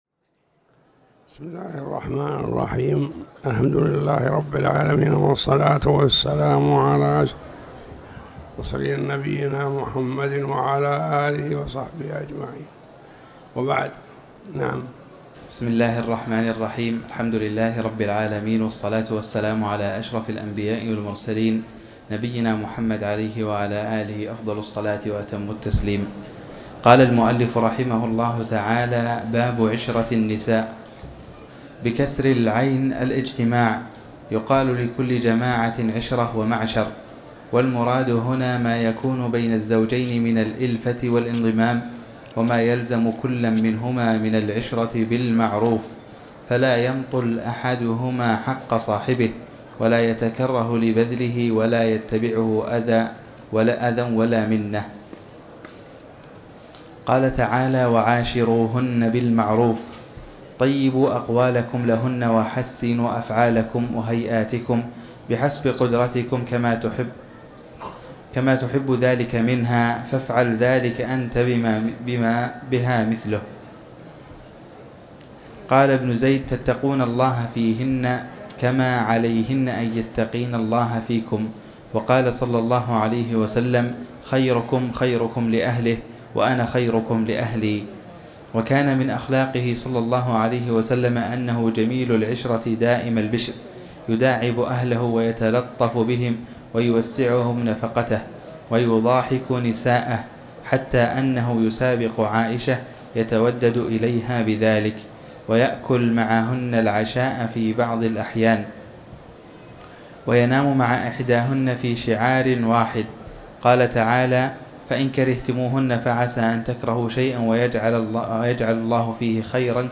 تاريخ النشر ١١ ذو القعدة ١٤٤٠ هـ المكان: المسجد الحرام الشيخ